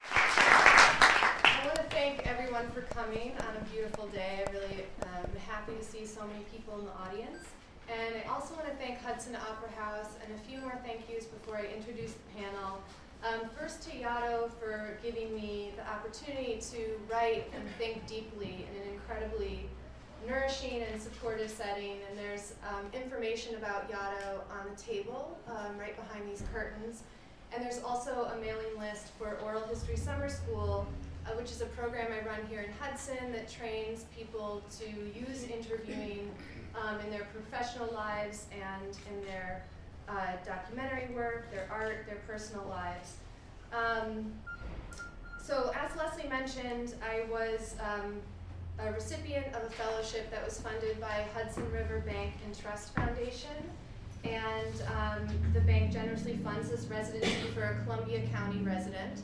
Hudson Hall at the Historic Hudson Opera House